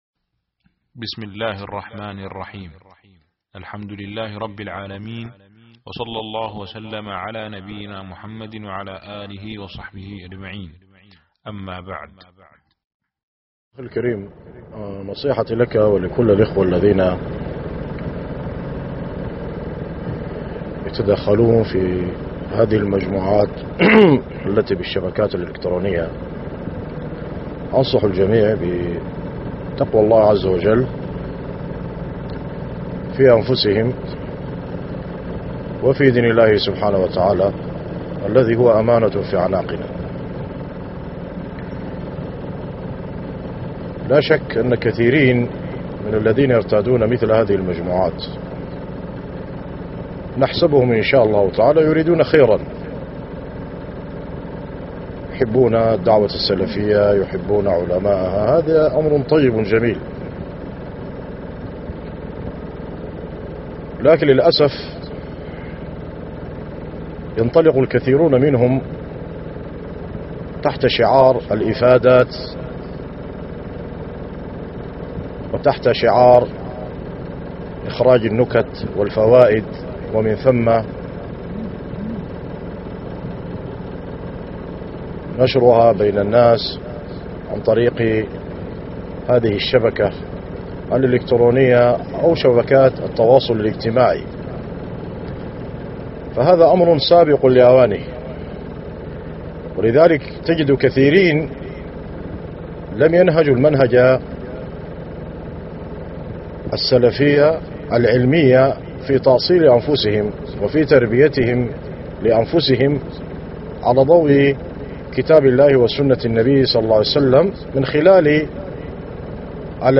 [كلمة توجيهية]